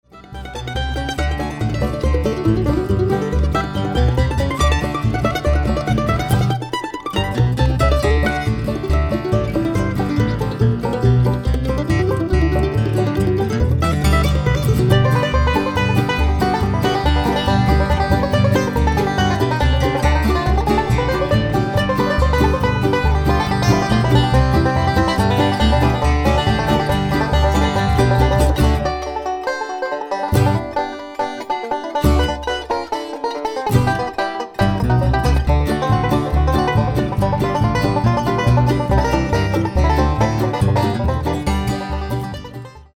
An instrumental album
mostly from the Bluegrass and Old-Time repertoire